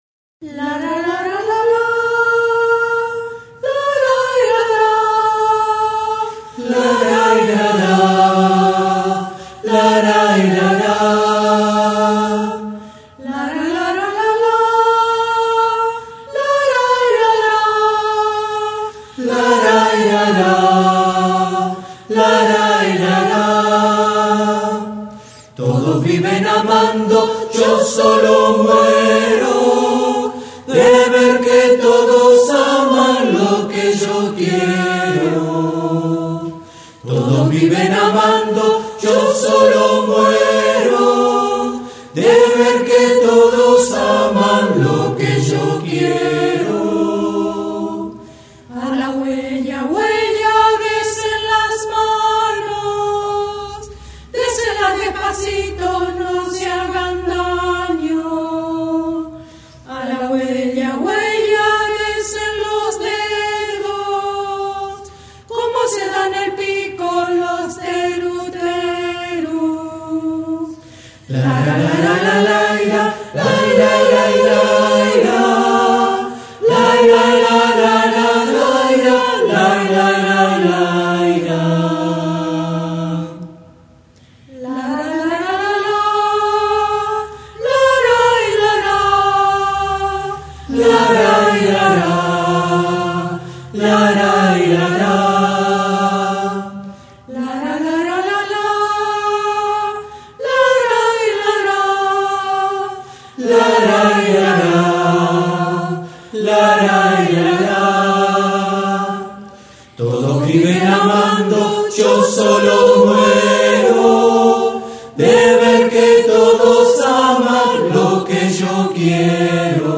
La Huella (danza sureña pampeana). Coral Abesti Beti.